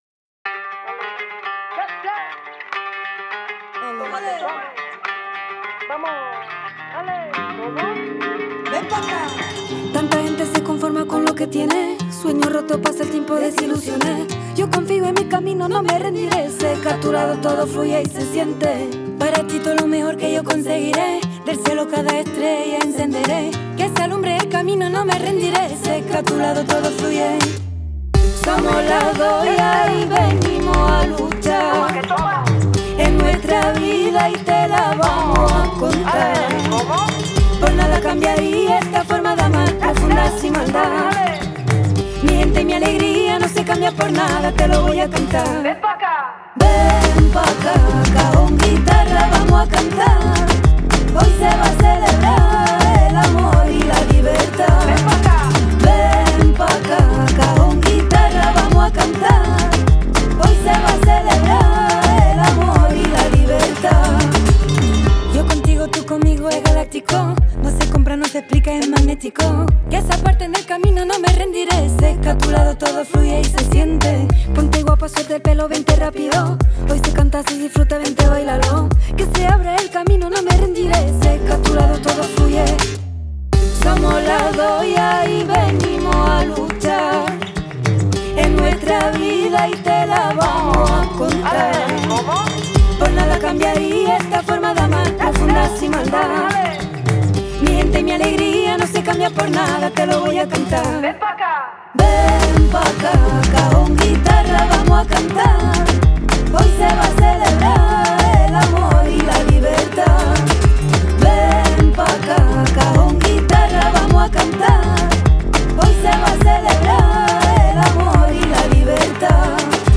mêlant tradition flamenco et modernité.